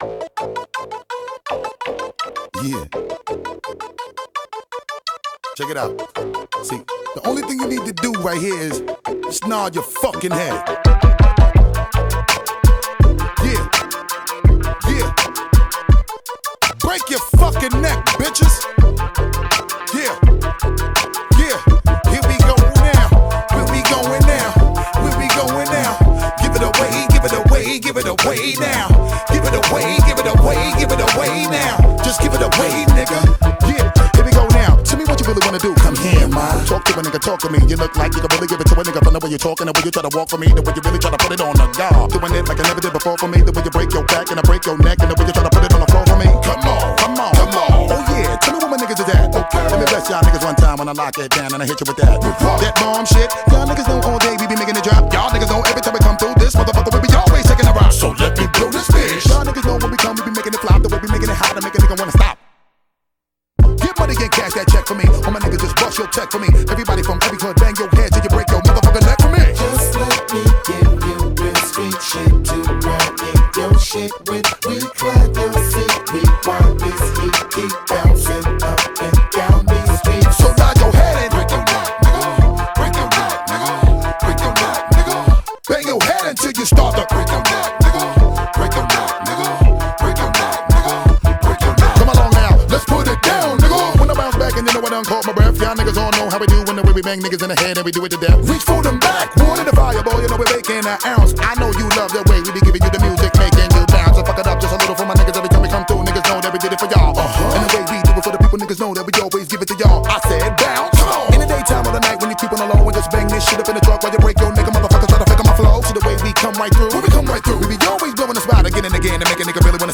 BPM166
MP3 QualityMusic Cut